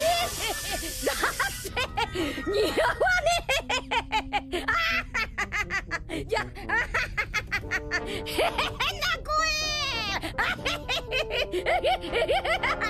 luffy-dondurma-gulme-online-audio-converter.mp3